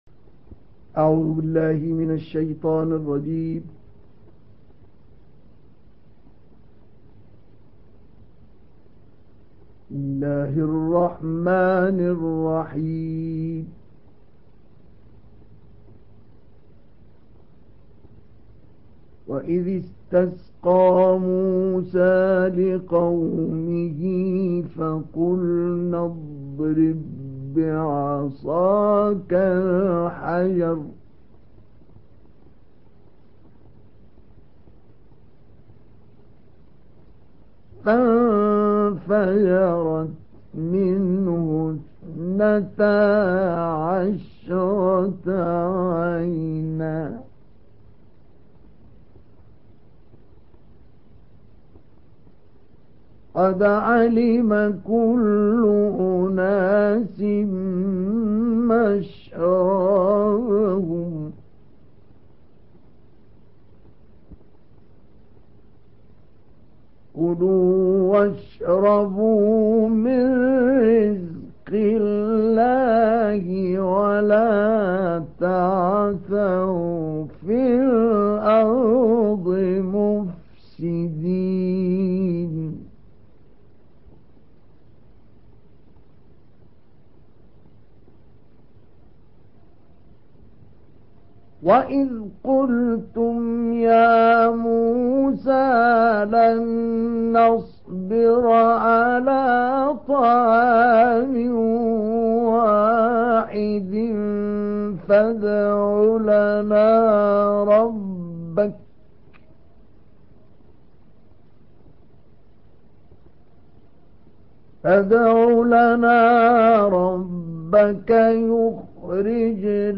دانلود تلاوت زیبای سوره بقره آیات 60 الی 82 با صدای دلنشین استاد شیخ مصطفی اسماعیل
در این بخش از ضیاءالصالحین، تلاوت زیبای آیات 60 الی 82 سوره مبارکه بقره را با صدای دلنشین استاد شیخ مصطفی اسماعیل به مدت 27 دقیقه با علاقه مندان به اشتراک می گذاریم.